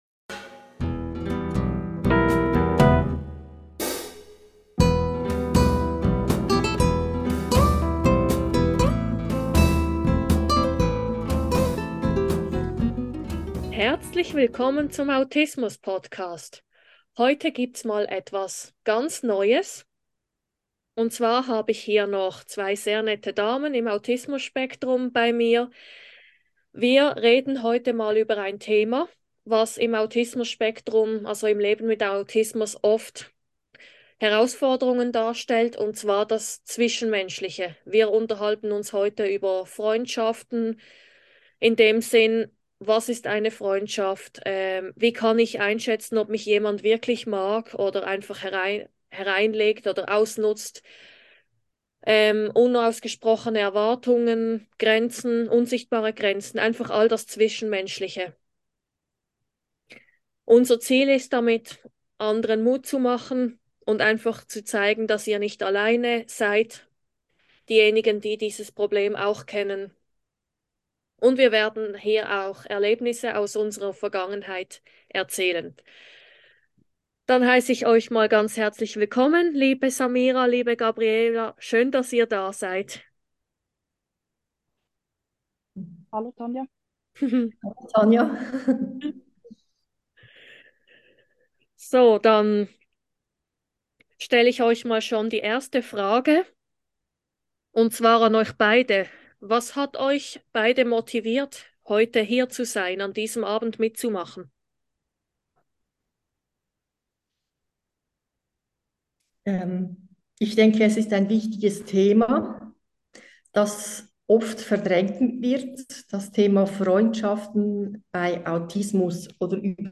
In dieser Podcastfolge tausche ich mich mit zwei weiteren Damen im Autismus Spektrum über diese Thematik aus. Wir reden über unsere Erfahrungen.